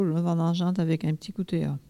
Bois-de-Céné
collecte de locutions vernaculaires